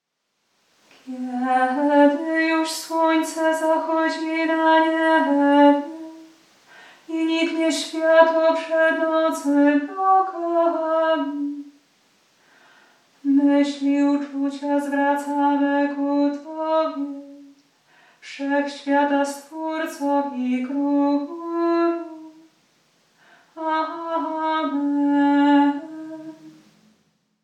ton schematyczny:
Hymn_Schemat_Kiedy-juz-slonce-kjmqmats.mp3